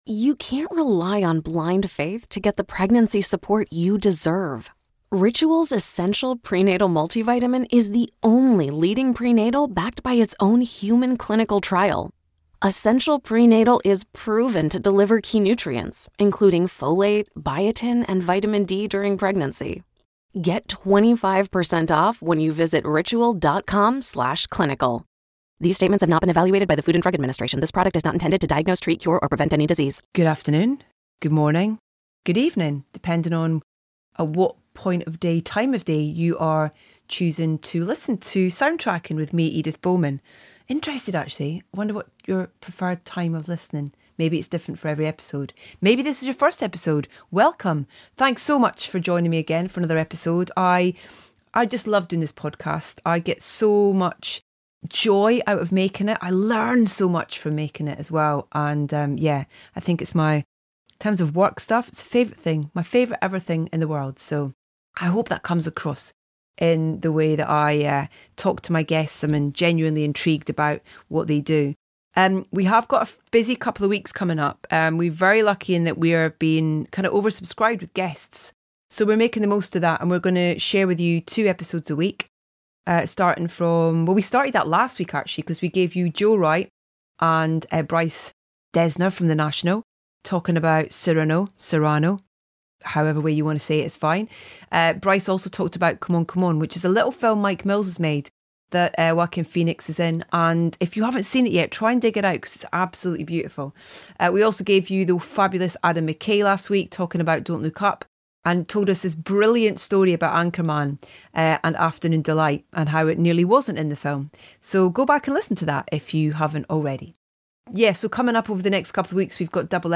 Rock 'n' roll royalty joins Edith for the latest episode of Soundtracking, as Dave Grohl discusses his comedy horror Studio 666 among many other things.